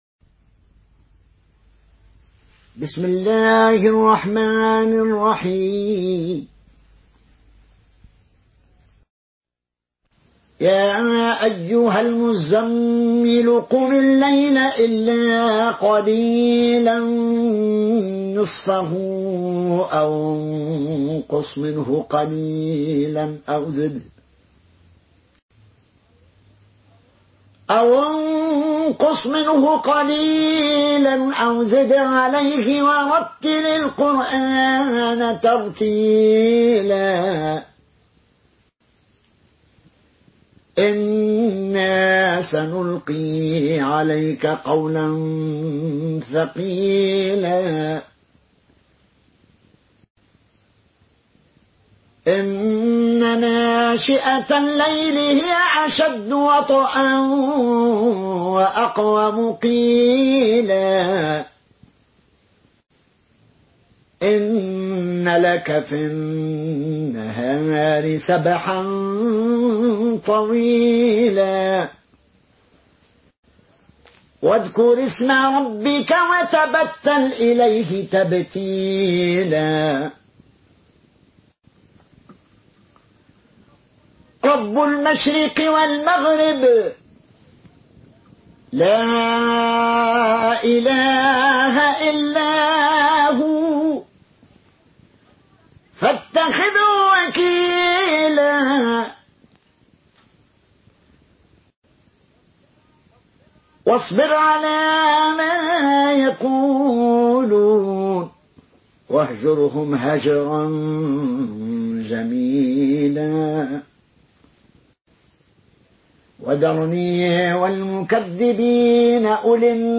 Une récitation touchante et belle des versets coraniques par la narration Qaloon An Nafi.
Murattal